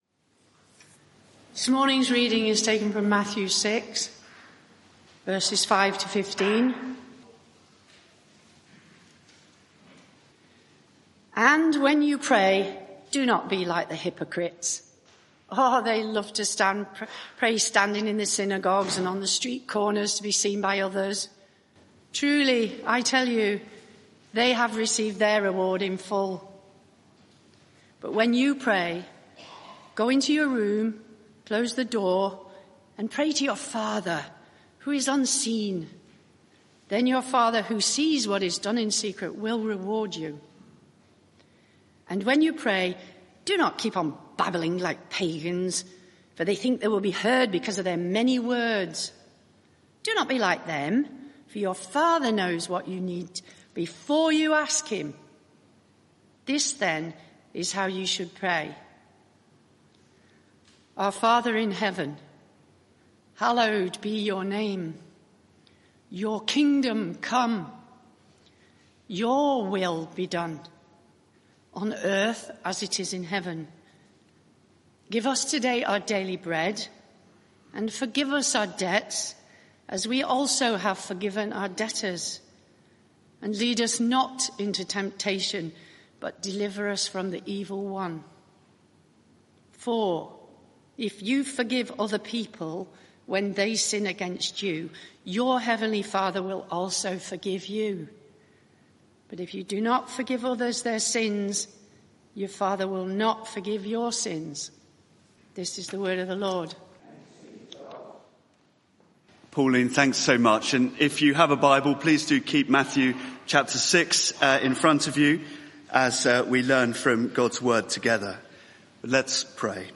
Media for 11am Service on Sun 27th Apr 2025 11:00 Speaker
Sermon (audio) Search the media library There are recordings here going back several years.